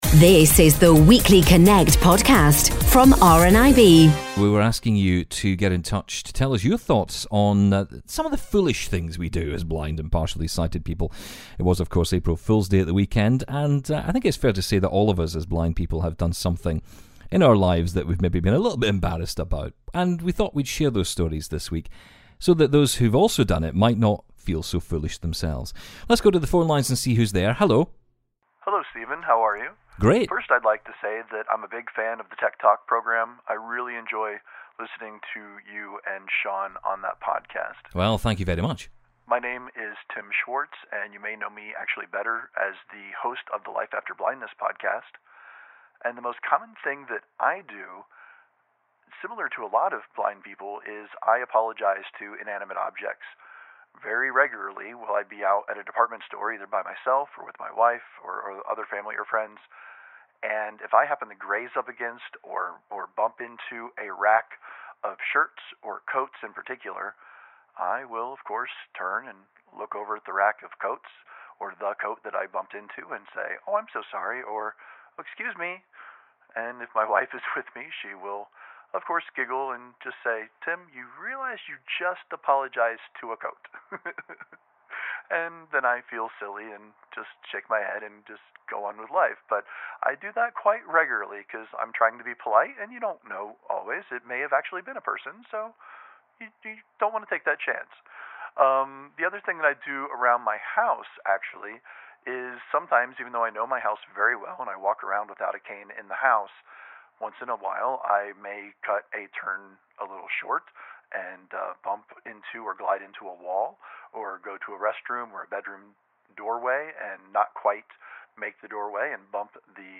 The start of April is always known as the silly season for crazy and funny stories that just can't be true. This week we hear from blind and partially sighted people who tell us their stories of foolishness - things they have done due to their blindness - in an attempt to make others feel less foolish!